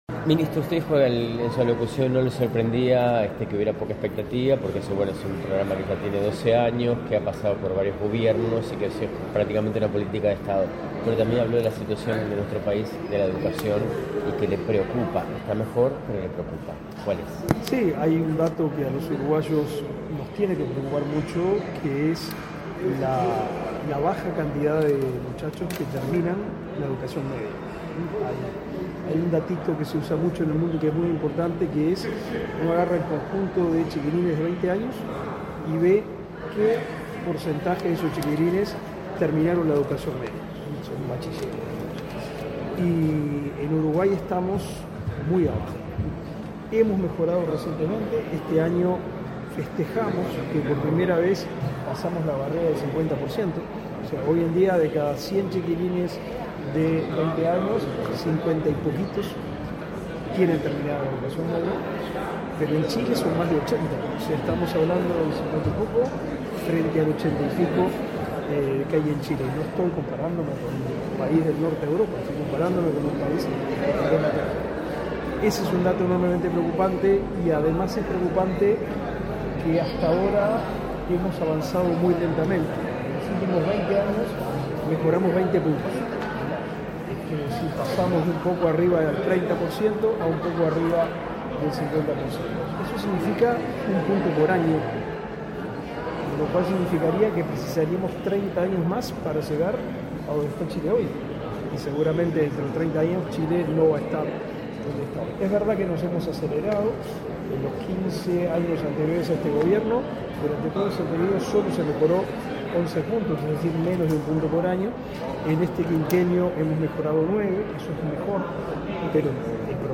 Declaraciones de prensa del ministro del MEC, Pablo da Silveira.
Declaraciones de prensa del ministro del MEC, Pablo da Silveira. 05/09/2024 Compartir Facebook X Copiar enlace WhatsApp LinkedIn El Ministerio de Trabajo y Seguridad Social (MTSS) presentó, este 4 de setiembre, una nueva convocatoria del programa Yo Estudio y Trabajo para estudiantes de entre 16 y 20 años sin experiencia laboral. Tras el evento, el titular del Ministerio de Educación y Cultura (MEC), Pablo da Silveira, realizó declaraciones a la prensa.